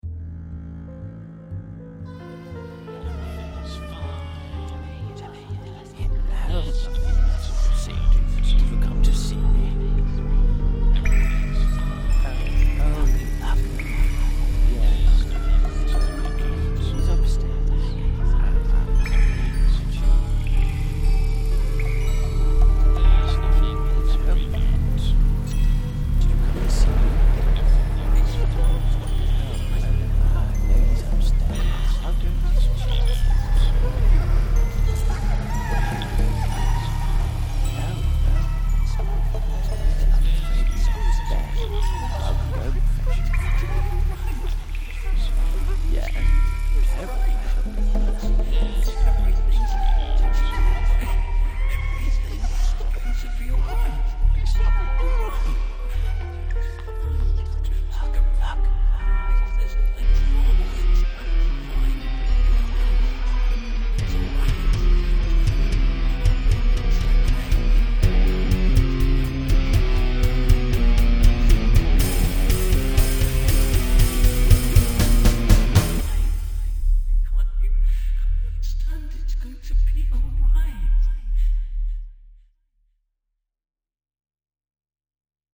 The "song" has a vocal element, but lyrics as such are optional.